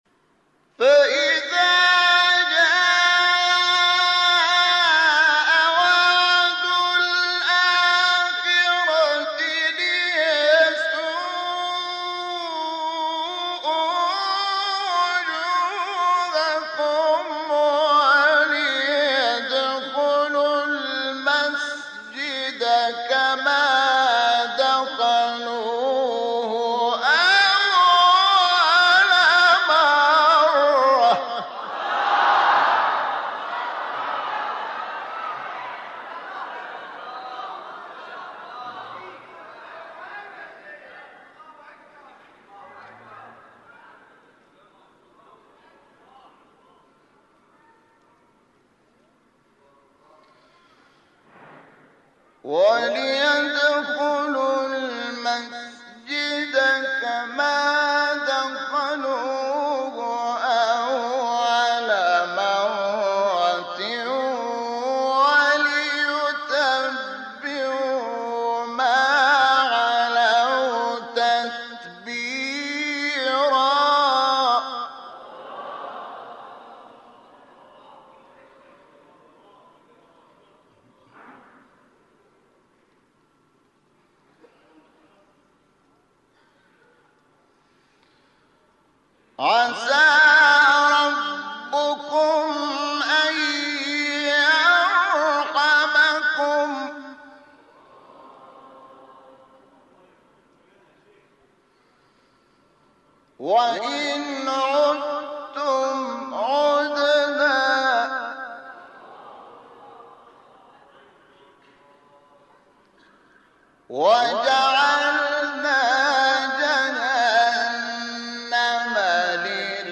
سوره : اسراء آیه: 7-11 استاد : شحات مقام : چهارگاه قبلی بعدی